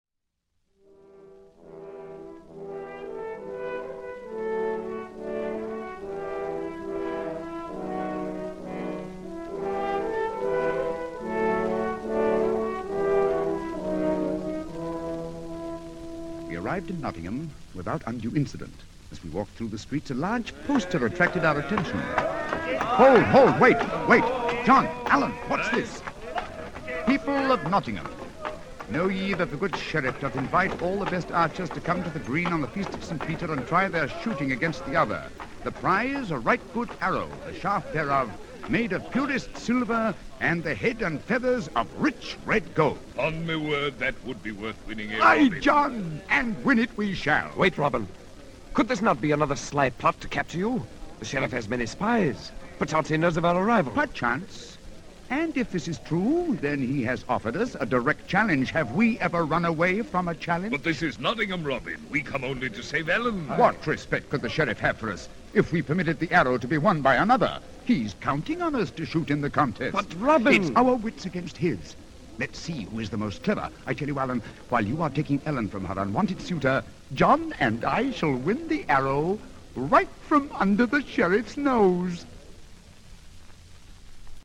Robin Hood - Basil Rathbone as Robin Hood (1 record, 33.3 rpm, Columbia ML 2063), recorded in 1949